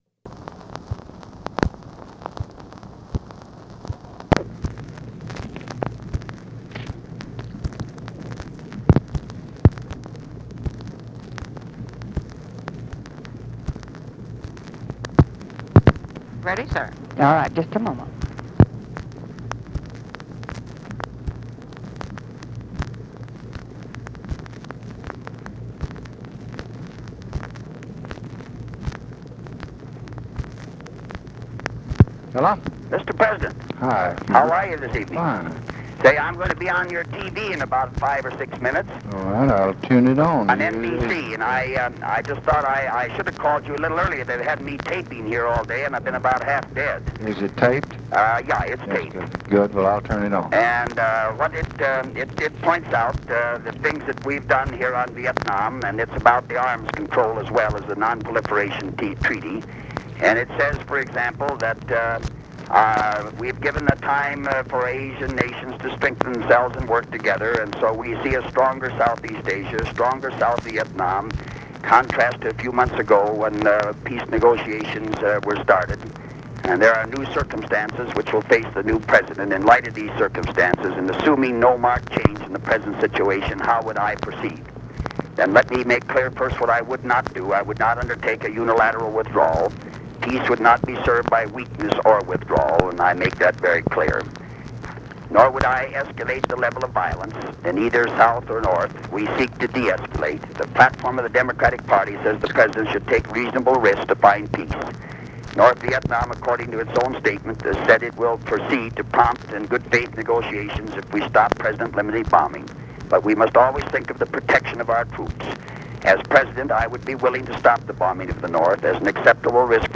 Conversation with HUBERT HUMPHREY and OFFICE SECRETARY, September 30, 1968
Secret White House Tapes